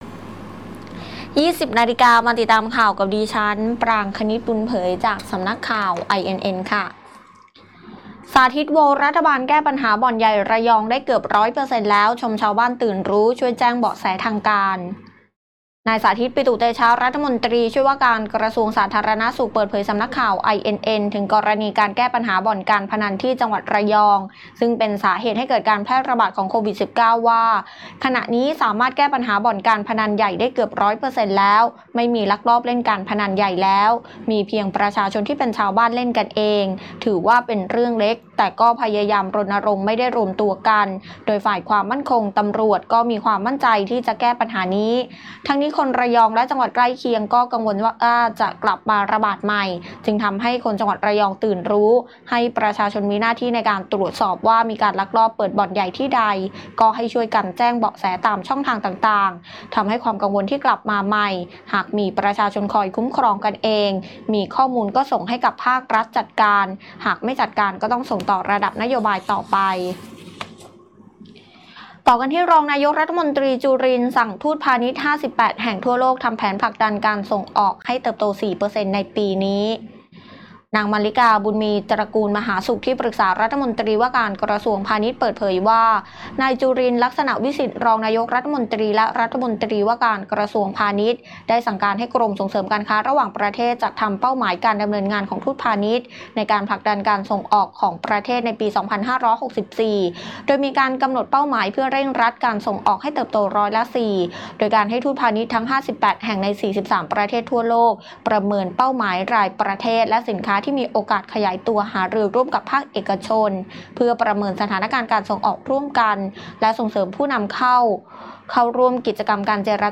คลิปข่าวต้นชั่วโมง